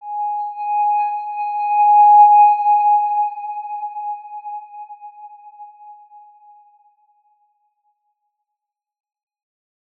X_Windwistle-G#4-ff.wav